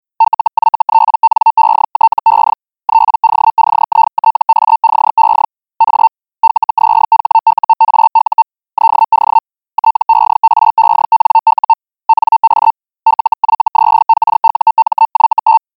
Jak asi přibližně mohl znít signál radiomajáku ve sluchátkách, si můžete poslechnout i dnes. Jedná se samozřejmě o simulaci, nikoliv o historický audiozáznam - takový nejspíš nikde neexistuje.
5x rychlejší modifikace .